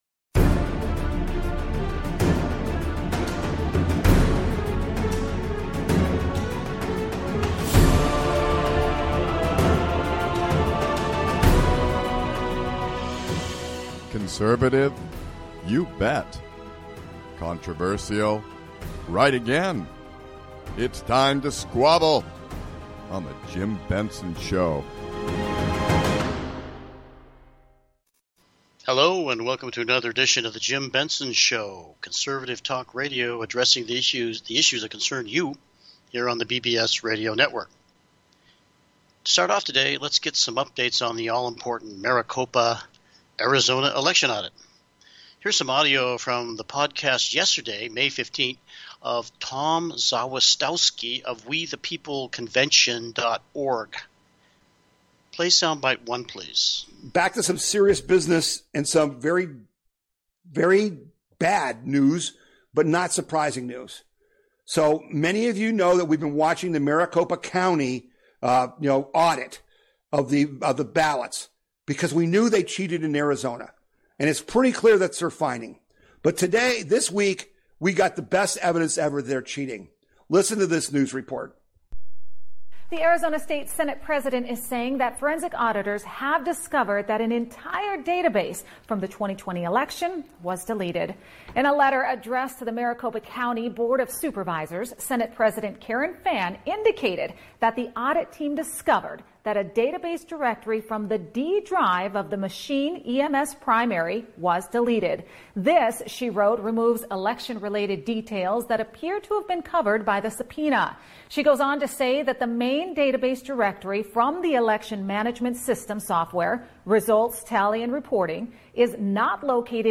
Talk Show Episode, Audio Podcast
conservative talk radio done right, addressing the issues that concern you.